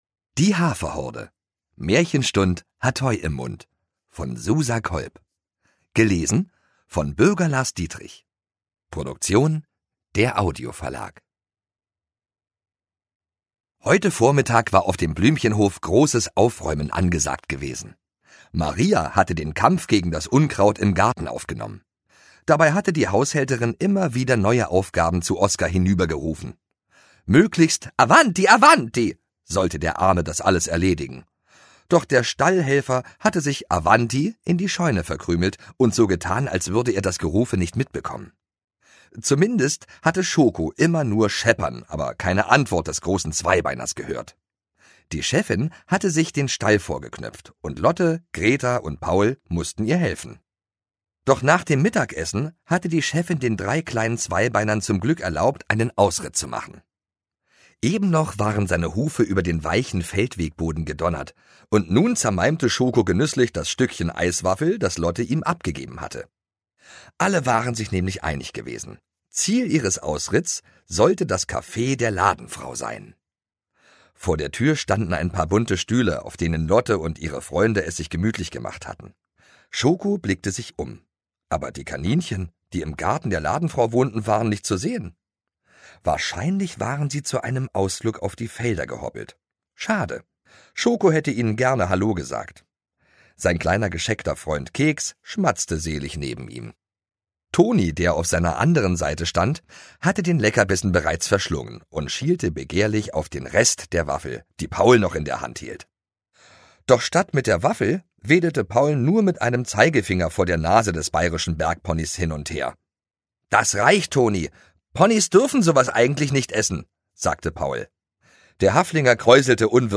Hörbuch
Die Haferhorde – Teil 17: Märchenstund‘ hat Heu im Mund Ungekürzte Lesung mit Bürger Lars Dietrich
Bürger Lars Dietrich (Sprecher)